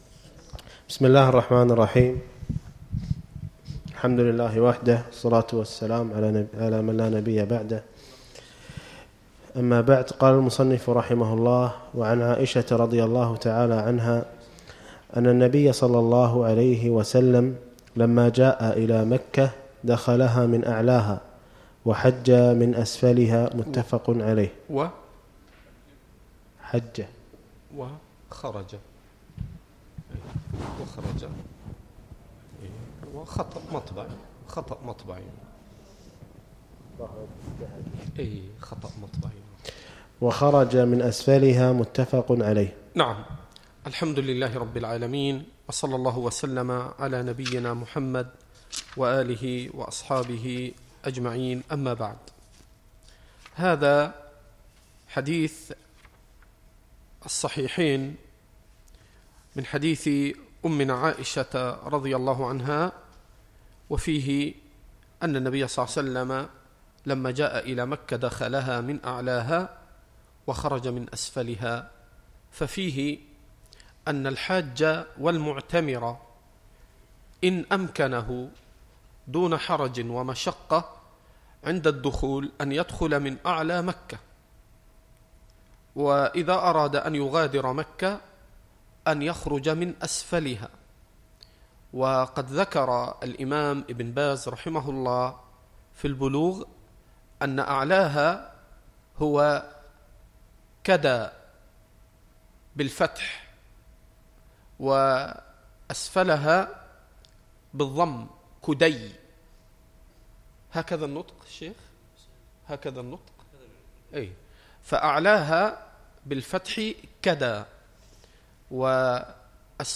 الدرس الرابع عشر - شرح كتاب الحج من بلوغ المرام